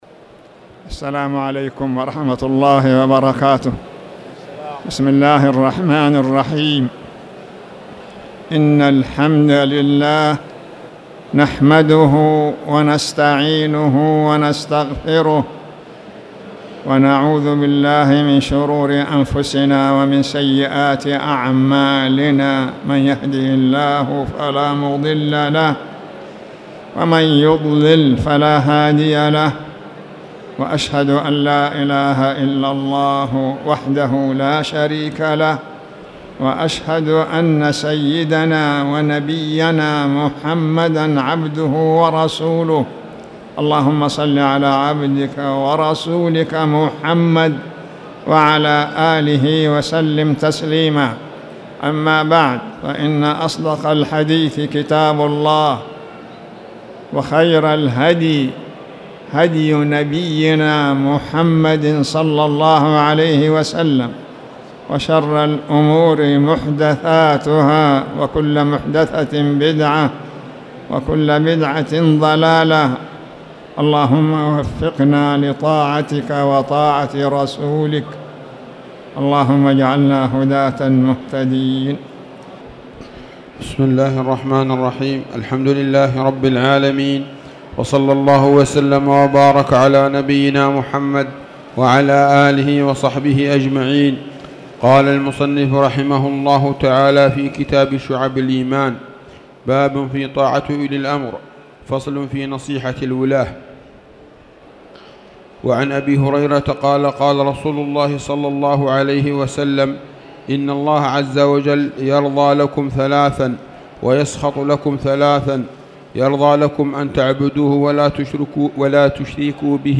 تاريخ النشر ٢٣ ذو الحجة ١٤٣٨ هـ المكان: المسجد الحرام الشيخ